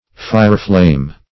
fireflame - definition of fireflame - synonyms, pronunciation, spelling from Free Dictionary Search Result for " fireflame" : The Collaborative International Dictionary of English v.0.48: Fireflame \Fire"flame`\, n. (Zool.)
fireflame.mp3